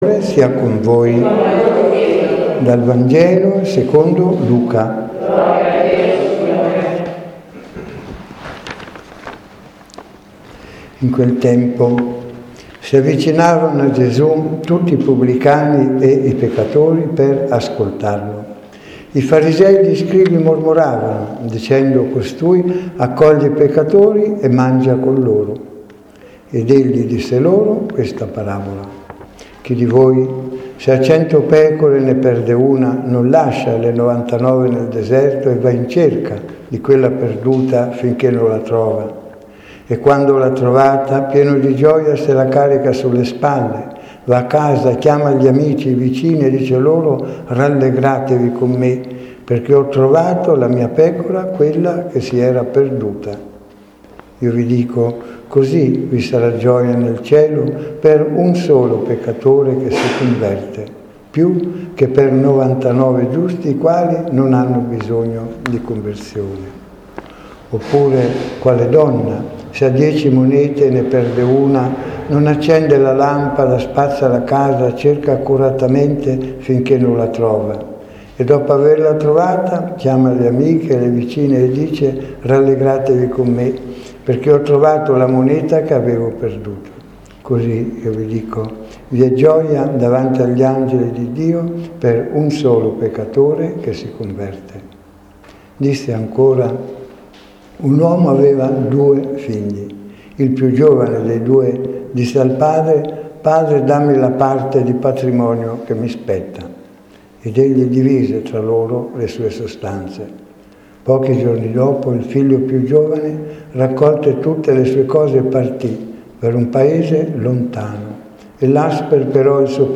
Addolorata 2016 - 18 settembre - XXV T.O./C Clicca sul link con il simbolo mp3 per ascoltare l'omelia (P.S. Il Profeta erroneamente citato più volte non è Isaia ma l'amatissimo e tenace Amos.